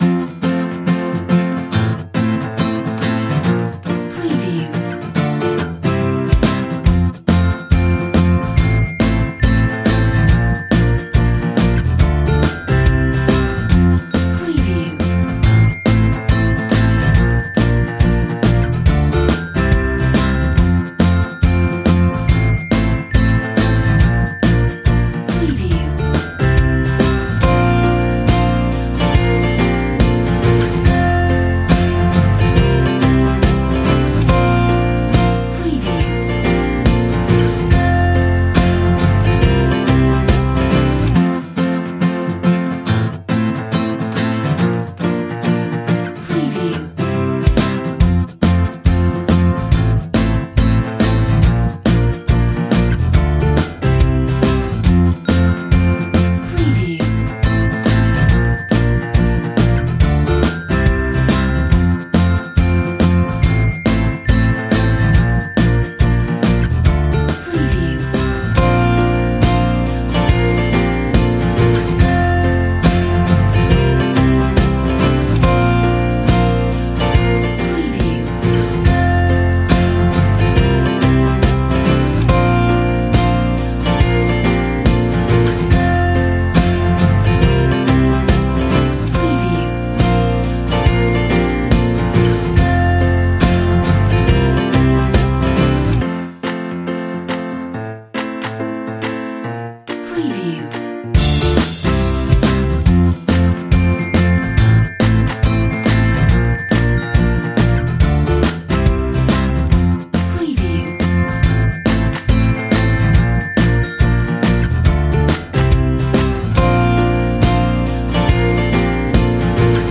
Happy upbeat acoustic